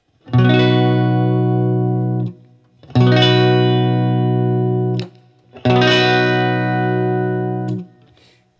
Guild-1994-X170-SB-BackAngleThis is a very bright guitar, but the tone controls are so useable that it’s an amazingly versatile instrument as well.
As usual, all recordings in this section were recorded with an Olympus LS-10. The amp is an Axe-FX Ultra set to “Tiny Tweed” which was left stock.
Barre B on All 3
Moving up the fretboard, I recorded a simple B barre chord at the 7th fret using all three pickups.